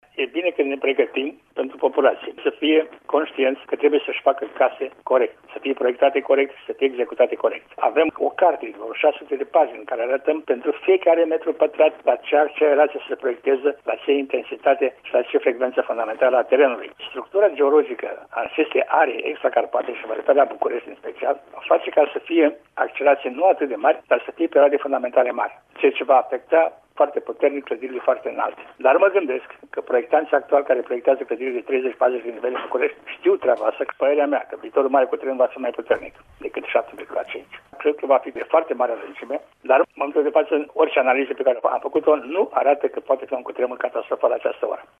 Invitat la Radio România Actualităţi, el a precizat că astfel de exerciţii sunt importante pentru a se şti cum trebuie acţionat în asemenea situaţii.